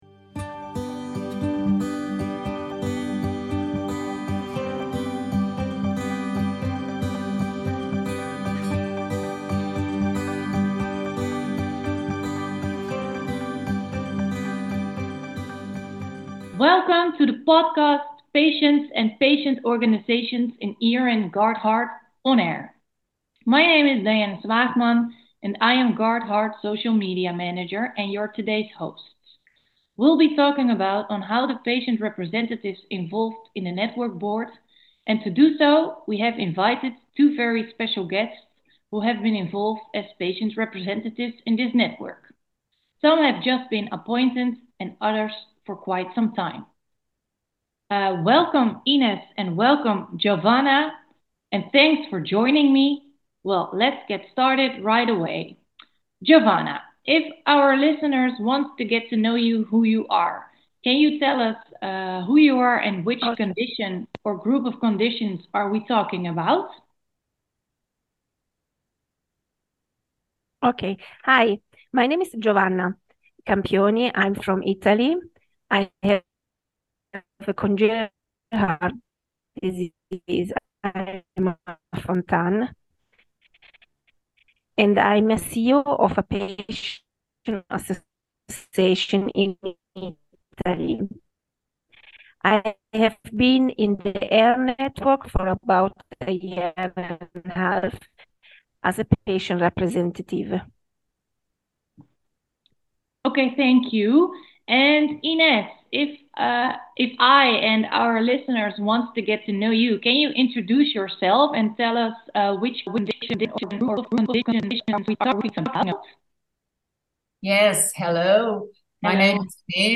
In March 2024 ERN GUARDHEART started with a podcast series in which all ePags or patient representatives are requested to participate. The idea is that in each recording, we are interviewing two representatives as a duo.